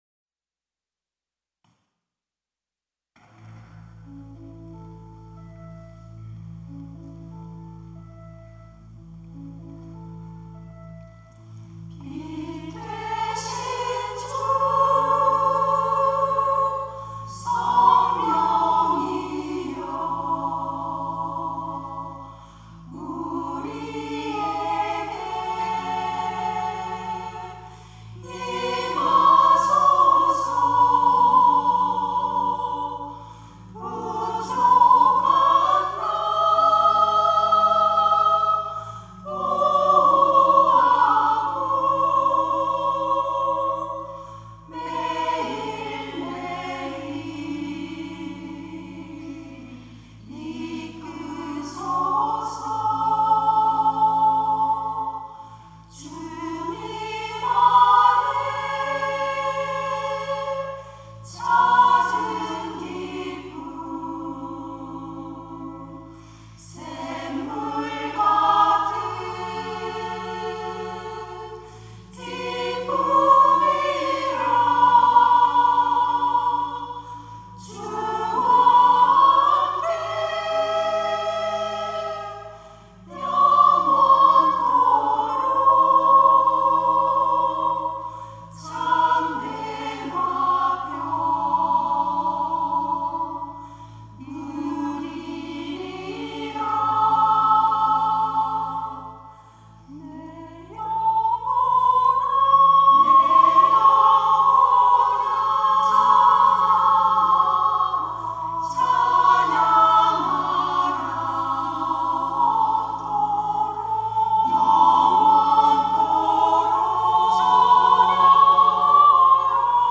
특송과 특주 - 임하소서 성령님
권사 합창단